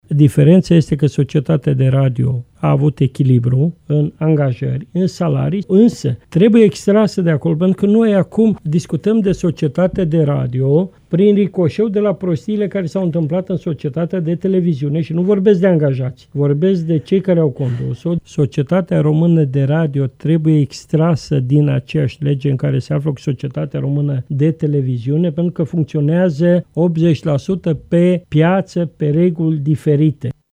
În replică, deputatul Partidul Mişcarea Populară, Petru Movilă, consideră că este necesară separarea legilor după care funcţioneaza cele doua instituţii şi nu a celor doua funcţii de preşedinte şi director general: